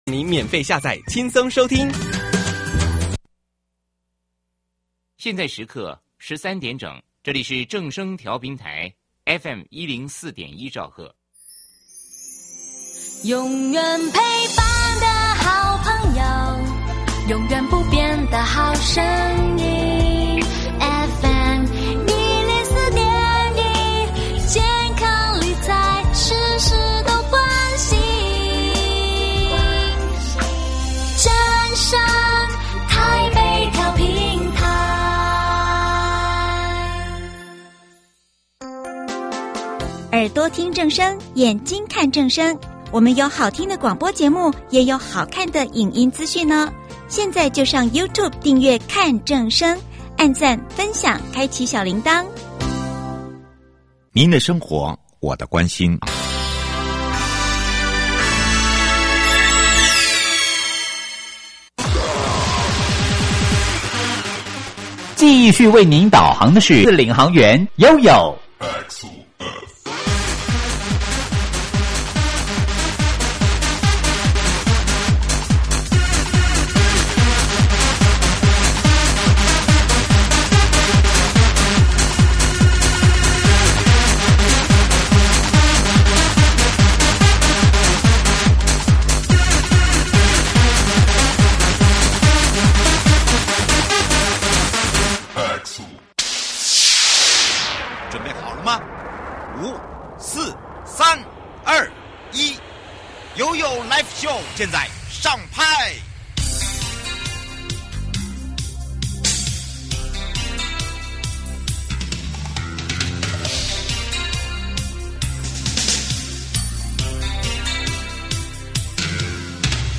受訪者： 營建你我他 快樂平安行~七嘴八舌講清楚~樂活街道自在同行!(四) 用心改變每一步——打造桃園人本安全